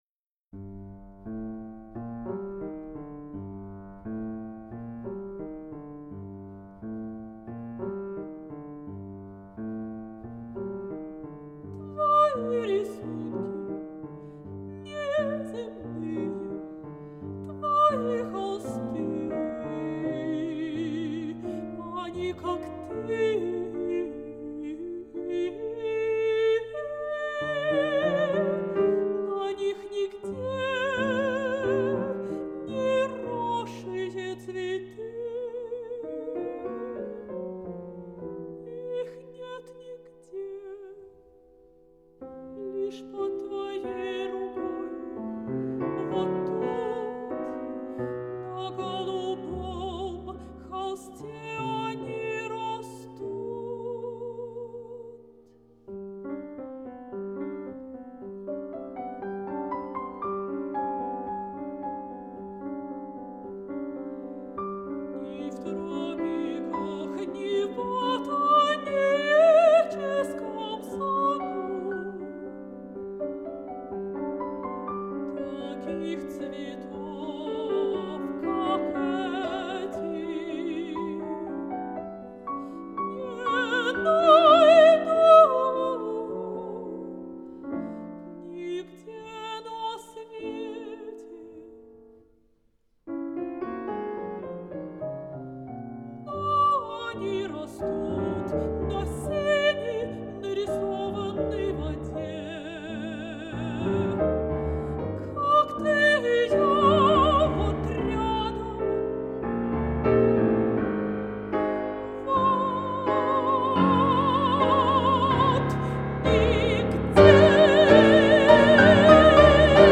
Вокальный цикл
Жанр: Классика/вокал